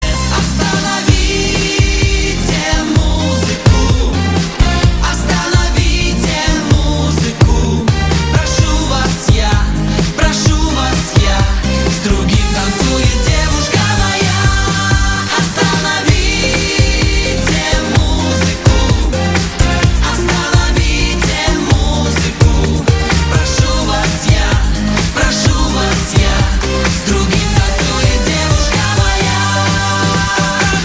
• Качество: 320, Stereo
80-е
ретро
танцевальная музыка